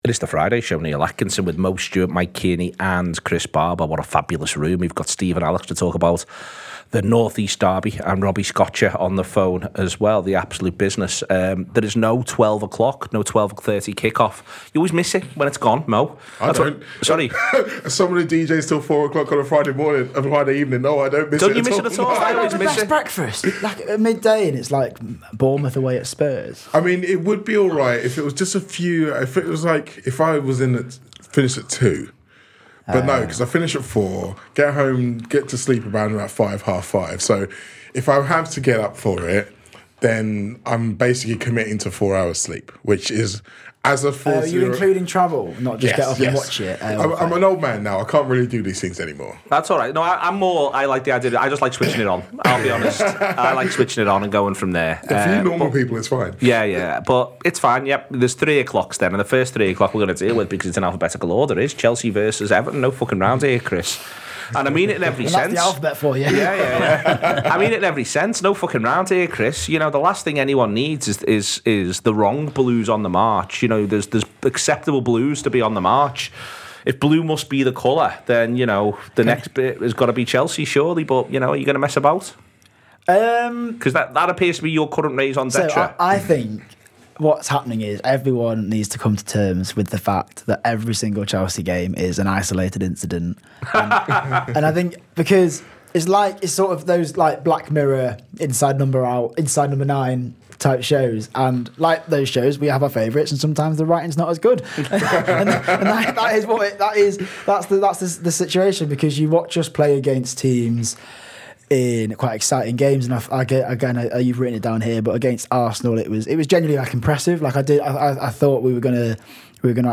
Premier League fans look ahead to the weekend’s games, including a Tyne-Wear derby with top four permutations.